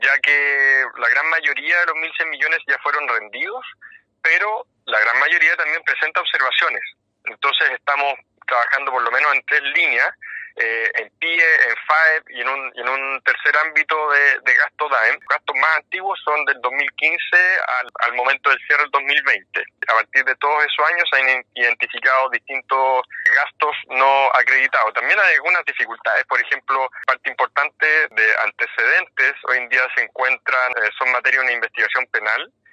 En el caso de Puerto Varas, se trata de 1.196 millones de pesos. Por esto, el jefe comunal, Tomás Garate, afirmó que se continúa acreditando los gastos, pero que ha sido un proceso engorroso para la actual administración, dado que por este tema se arrastran causas judiciales que está liderando la Fiscalía Local.